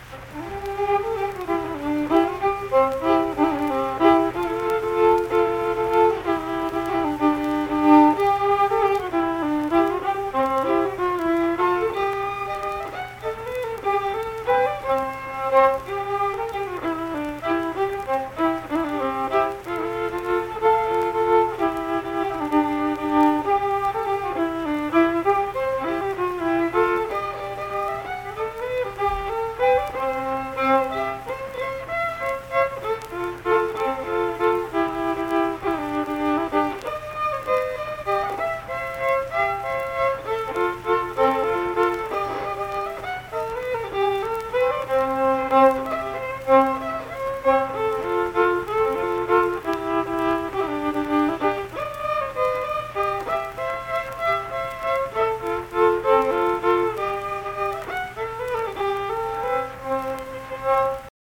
Waltz
Unaccompanied vocal and fiddle music
Instrumental Music
Fiddle